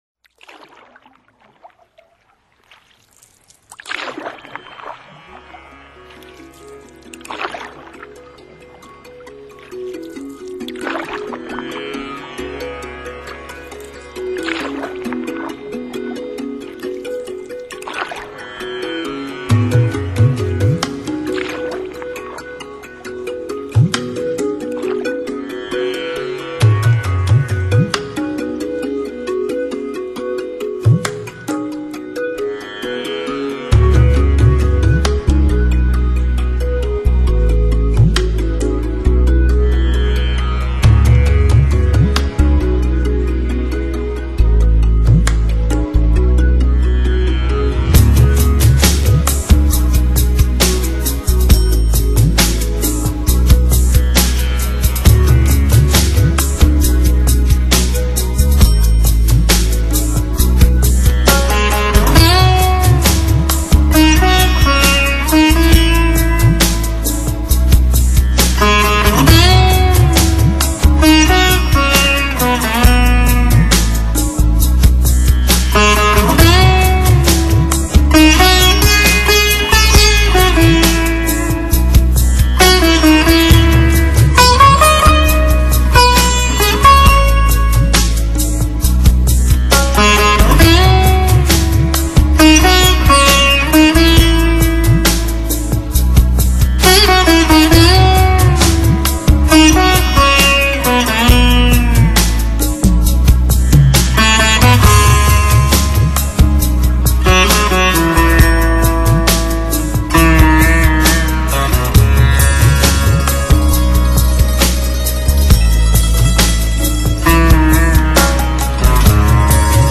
New Age, Meditative, World 专辑介绍：
，融合了东西方音乐文化，他们以娴熟的技巧演奏出精美的韵律，动感的音乐氛围让听众沐浴在这条伟大的生命之河。